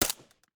sounds / weapons / _bolt / smg45_3.ogg
smg45_3.ogg